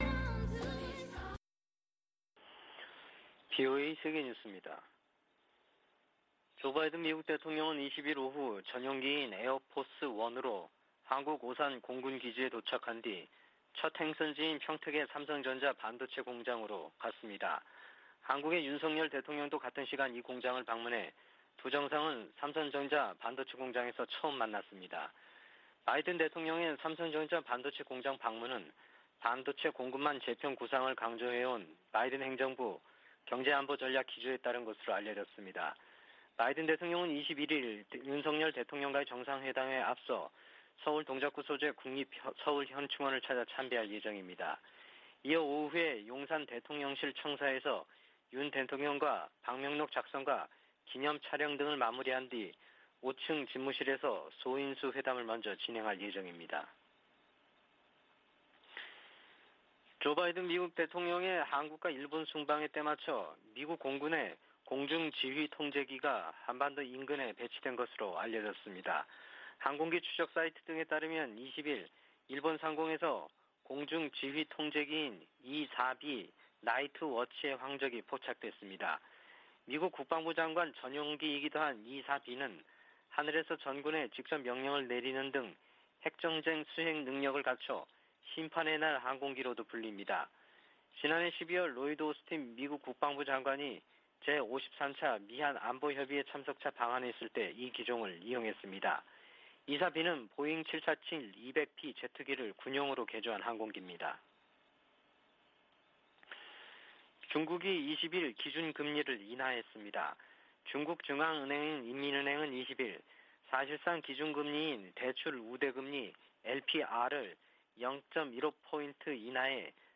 VOA 한국어 아침 뉴스 프로그램 '워싱턴 뉴스 광장' 2022년 5월 21일 방송입니다. 조 바이든 미국 대통령이 방한 첫 일정으로 평택 삼성전자 반도체 공장을 방문해 미한 간 기술동맹을 강조했습니다. 경제안보 현안과 대응전략을 논의하는 백악관과 한국 대통령실 간 대화채널을 구축하기로 했습니다. 미 국방부는 북한의 도발에 대응해 민첩한 대비태세를 취하고 있다고 밝혔습니다.